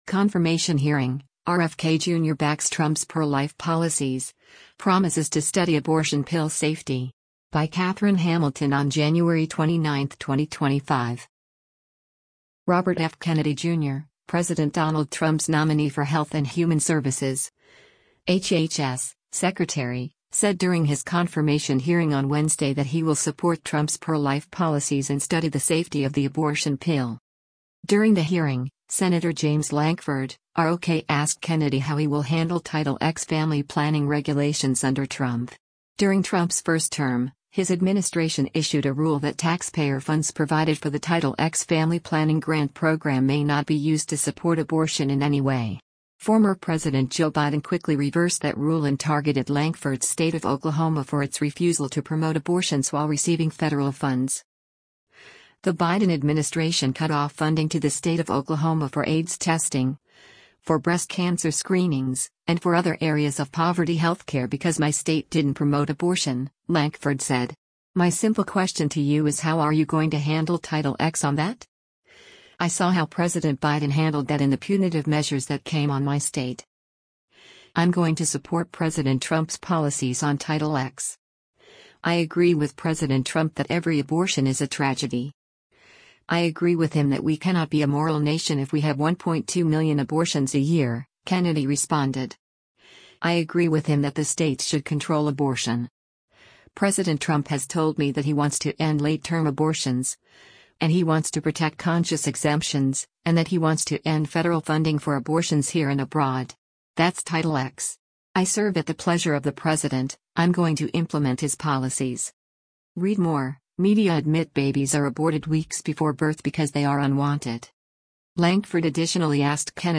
Confirmation Hearing: RFK Jr. Backs Trump’s Pro-Life Policies, Promises to Study Abortion Pill Safety